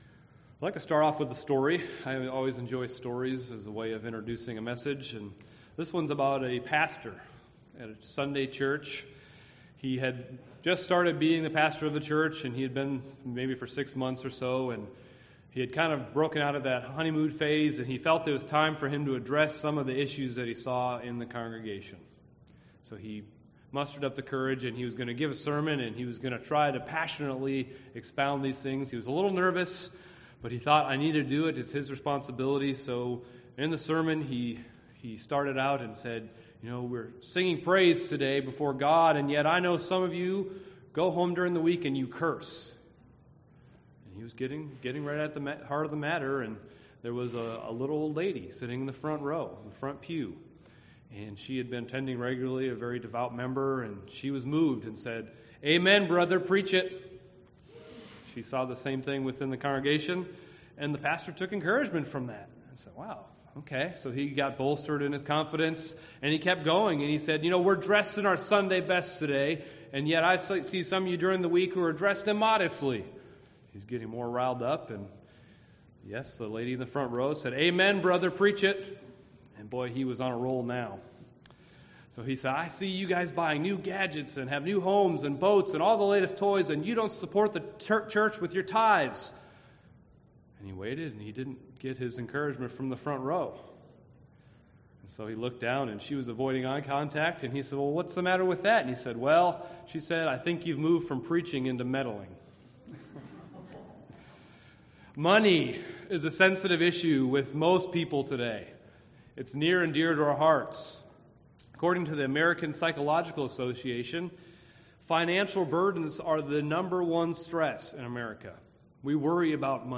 This sermon serves as a basic overview and reminder of those instructions.
Given in Milwaukee, WI